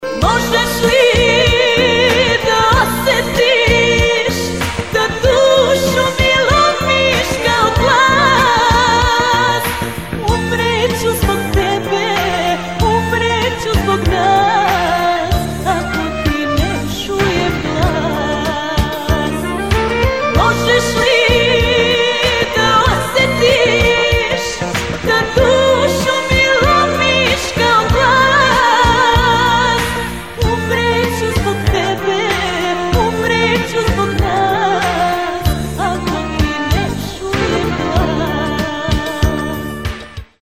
srceparajuća balada